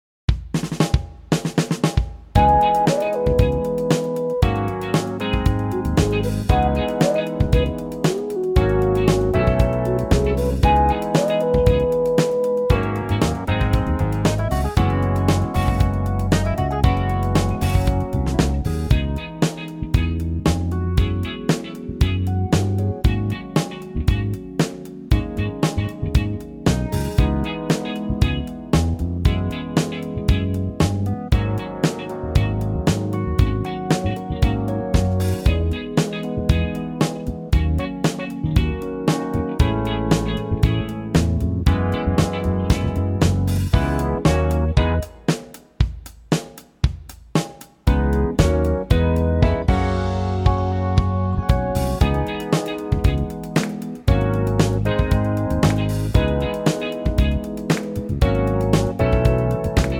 key E to F
8 bar intro and vocal in at 19 seconds
key - E to F - vocal range - D# to A
Suit higher male pop ranges.